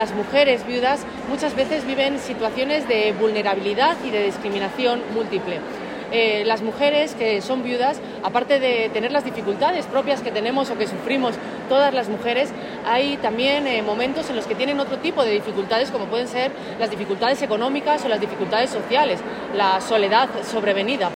>> En la XLIX Asamblea Regional de Viudas celebrada en La Guardia (Toledo)